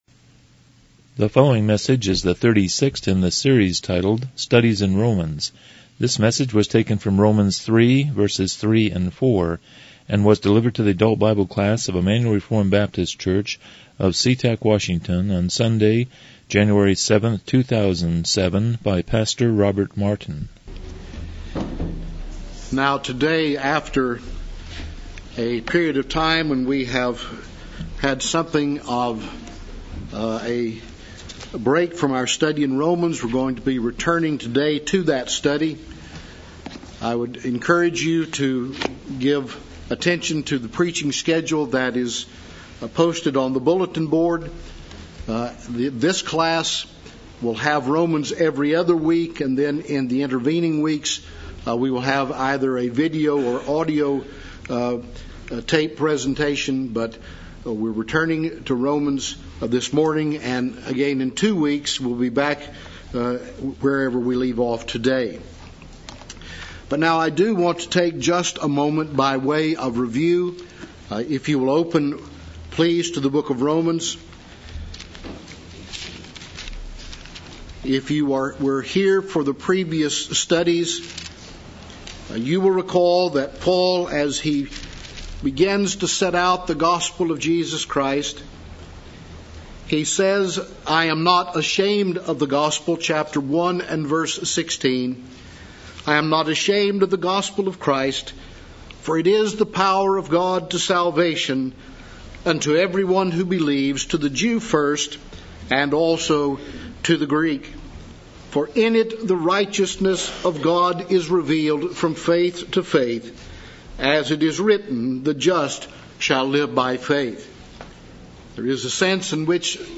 Romans 3:3-4 Service Type: Sunday School « 7 Chapter 1.2 & 1.3 The Identity of Scripture